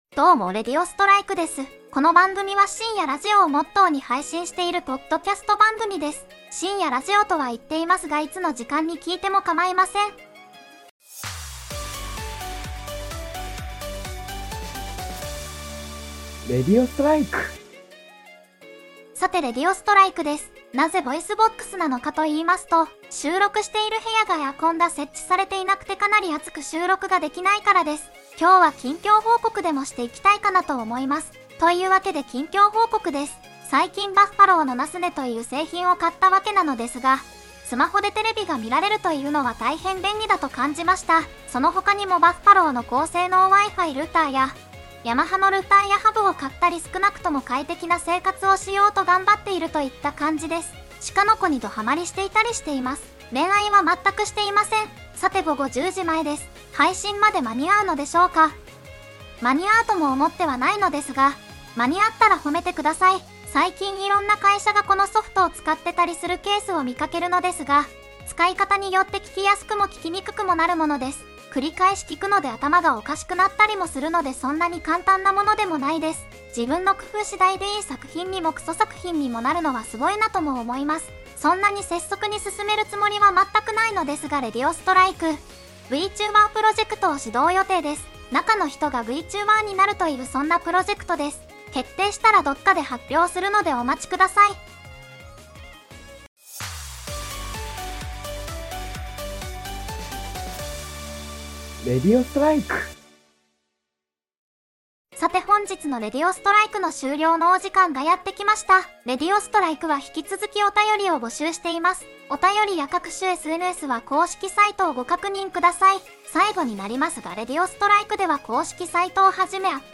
VOICEVOX:春日部つむぎ ニュースのお便りも募集しています。